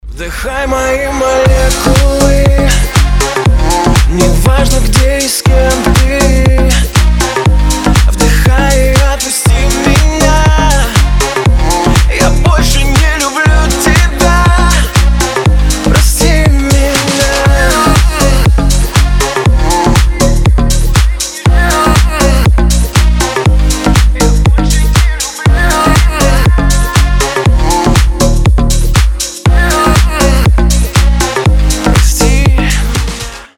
грустные
dance
house